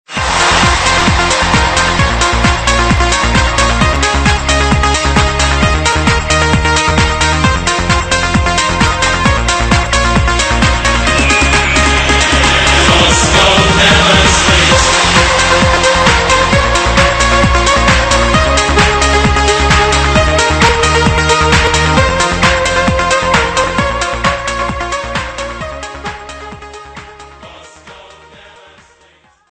Категория: Танцевальные